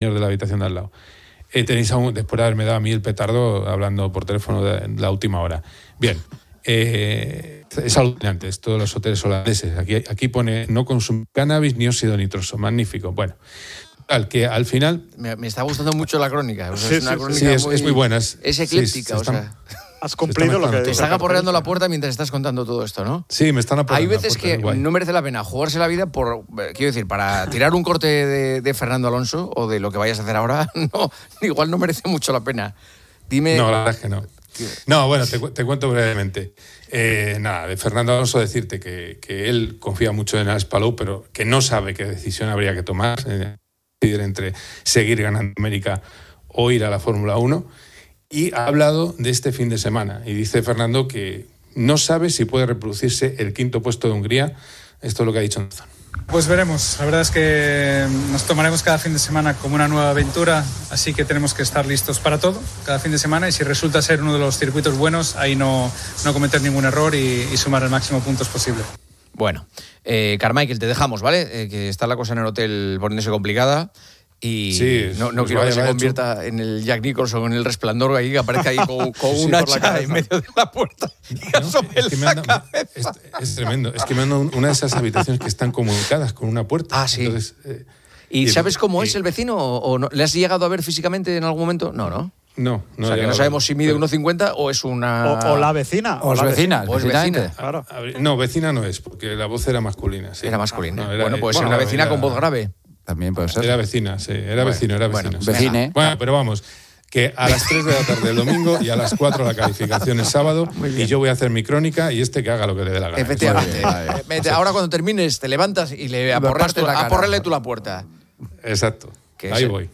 Resumen de los temas principales: La conversación abarca varias noticias y comentarios humorísticos.